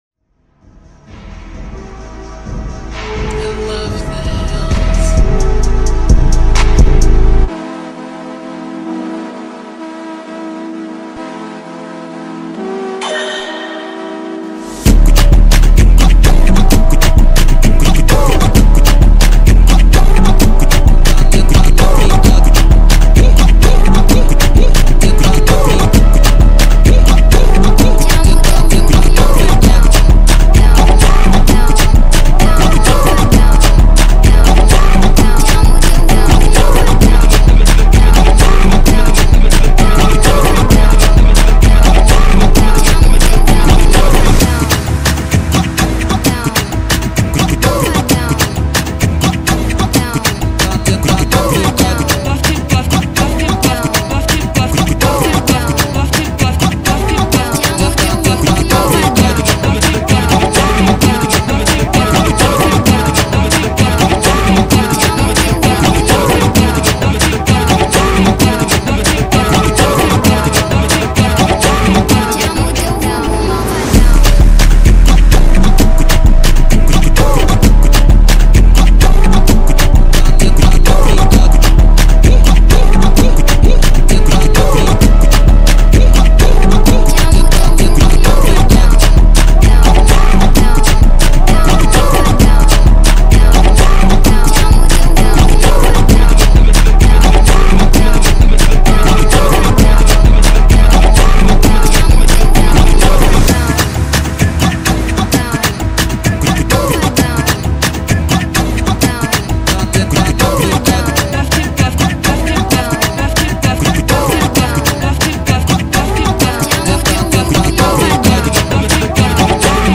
Brazil phonk 2024, Remix